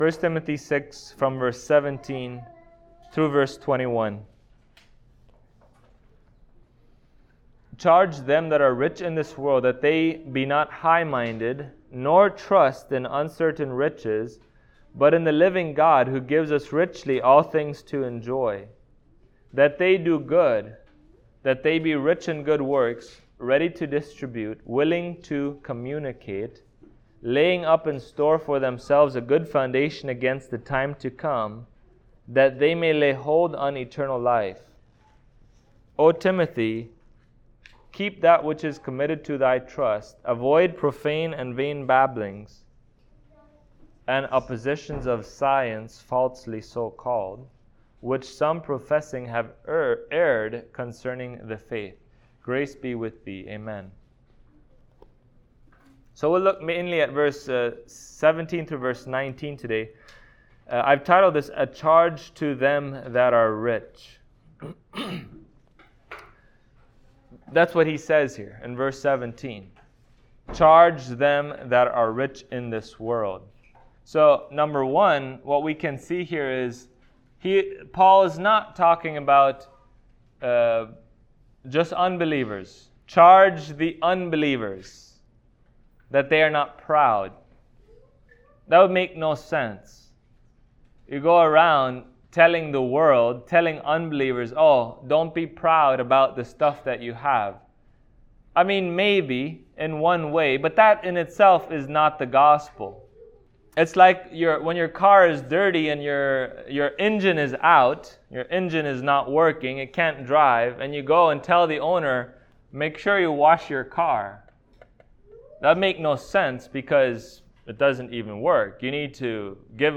1Tim 6:17-19 Service Type: Sunday Morning Christian